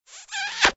AA_smooch_impact.ogg